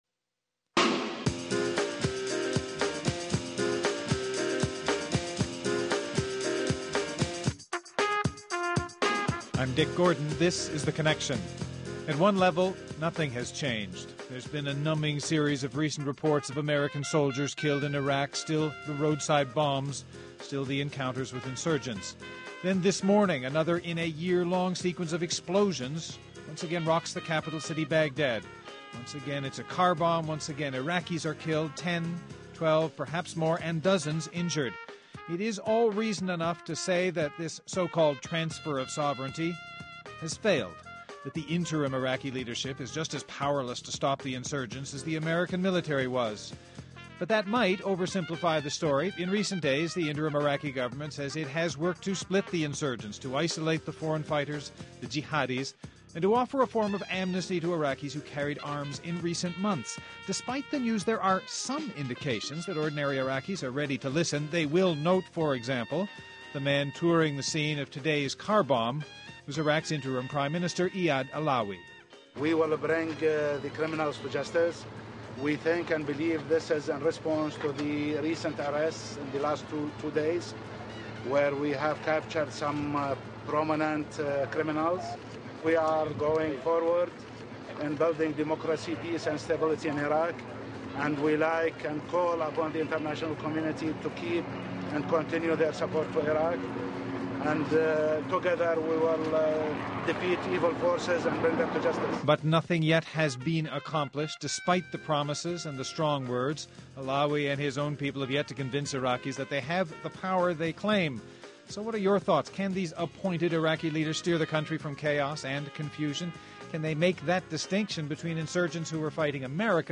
Guests: Peter Greste, Baghdad Correspondent for the BBC